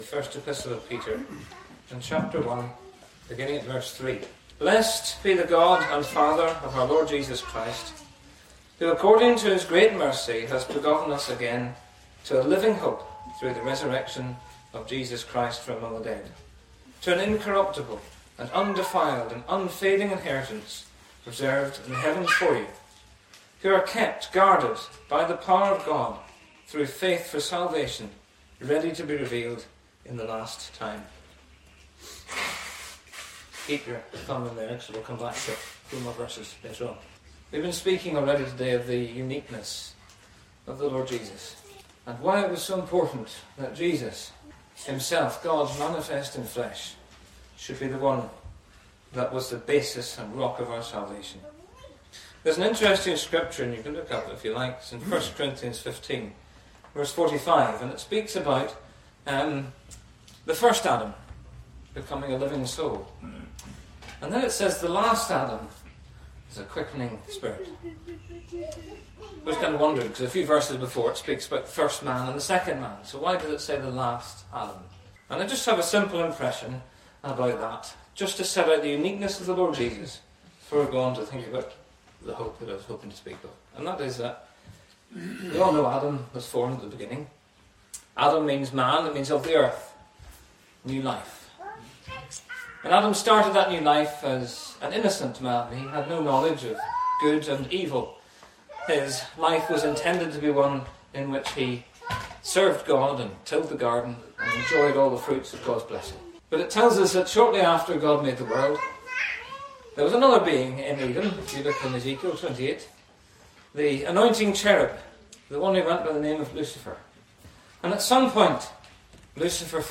It’s more than wishful thinking or vague optimism—it’s a confident expectation grounded in the resurrection of Jesus Christ. This address explores the power, assurance, and daily impact of this living hope, because the Lord Jesus lives.